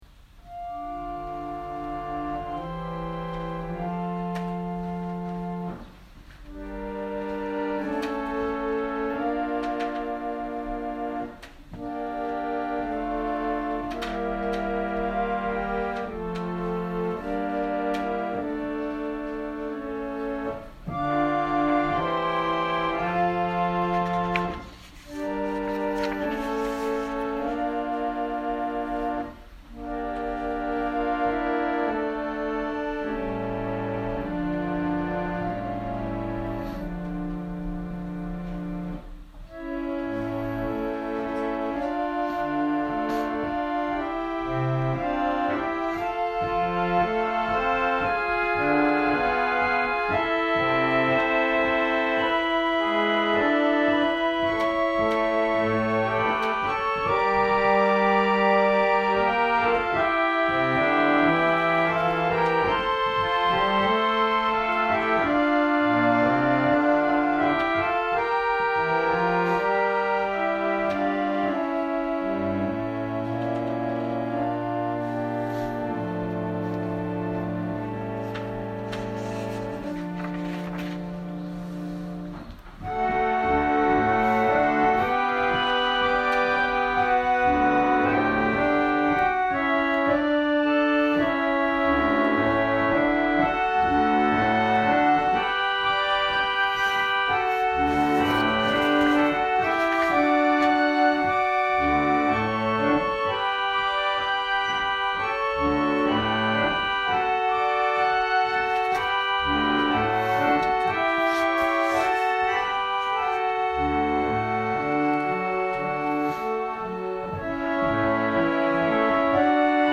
千間台教会。説教アーカイブ。
埼玉県春日部市のプロテスタント教会。